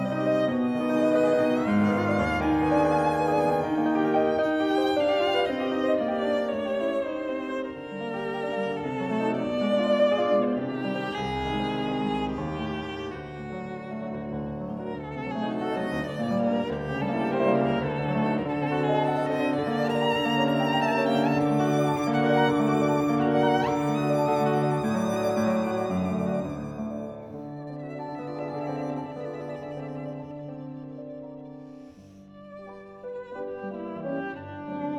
Струнные и рояль
Classical Romantic Era Piano
Жанр: Классика